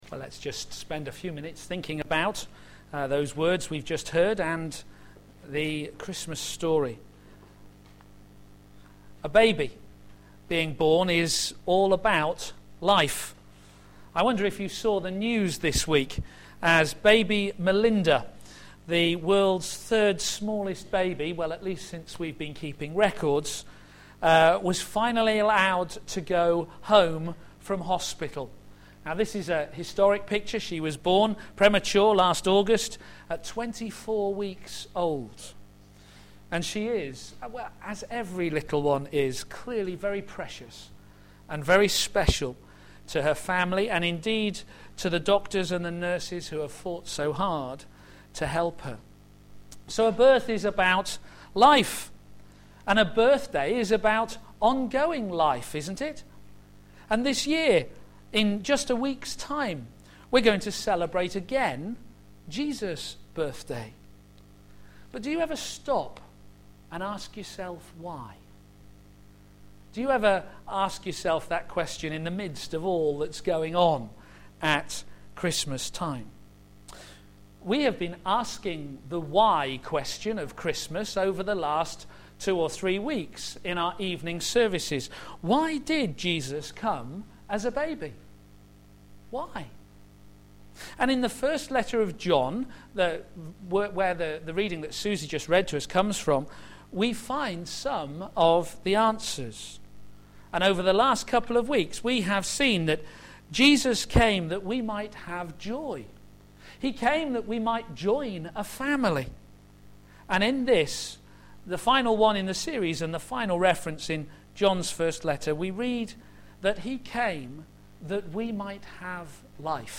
Theme: Immanuel: God with us for life Sermon